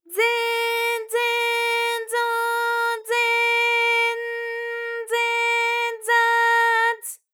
ALYS-DB-001-JPN - First Japanese UTAU vocal library of ALYS.
ze_ze_zo_ze_n_ze_za_z.wav